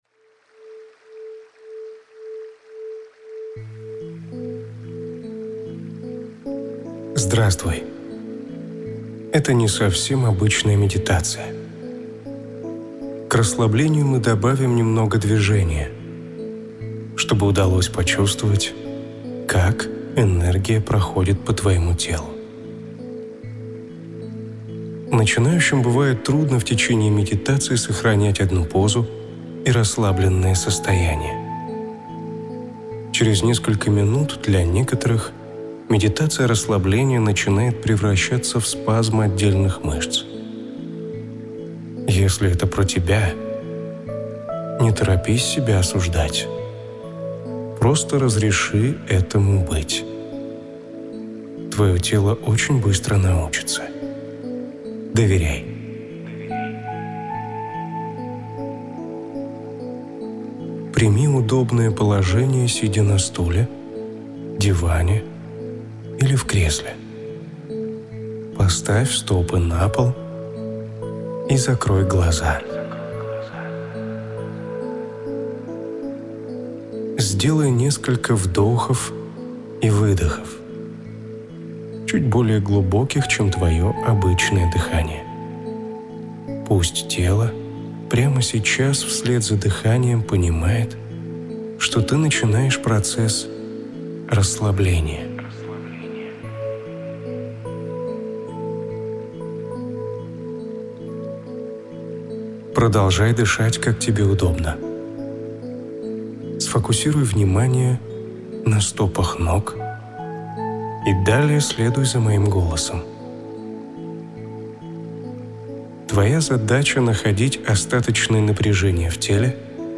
Уникальные аудио уроки и практики по медитации для восстановления энергии